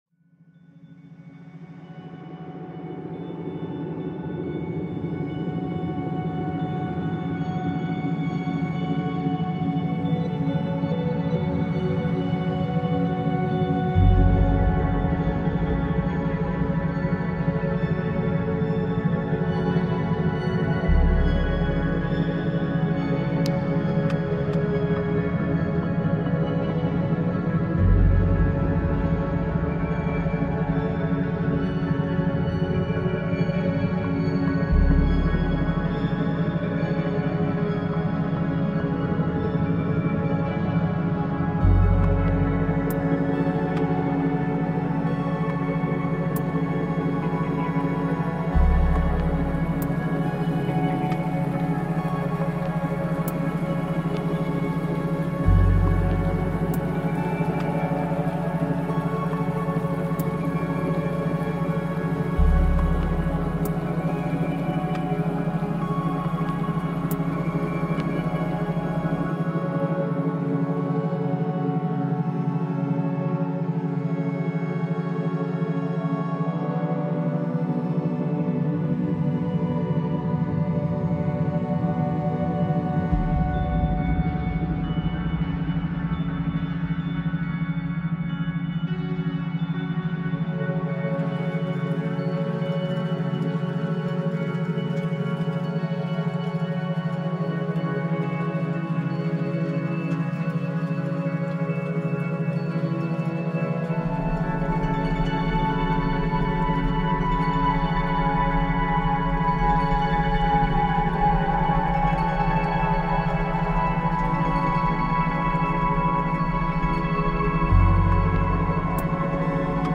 Creative Reading and Study Ambience with Forest Soundscapes